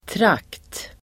Uttal: [trak:t]